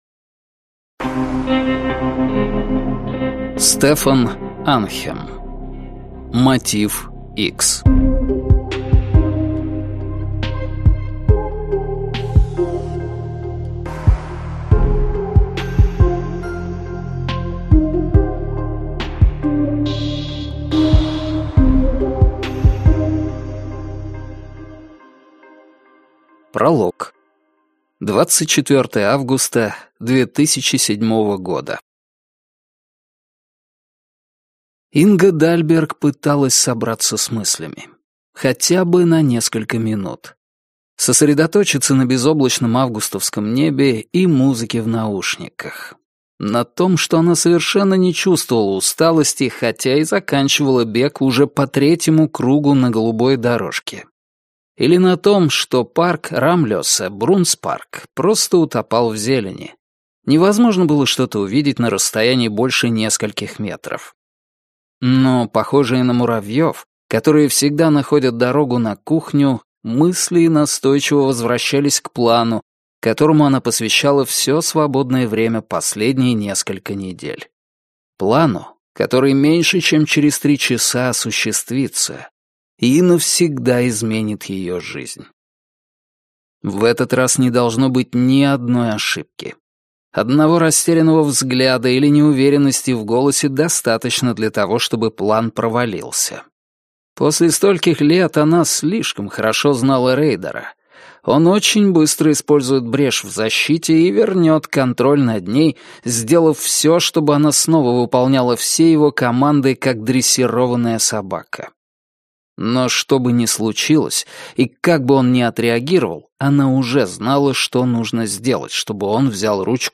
Аудиокнига Мотив Х | Библиотека аудиокниг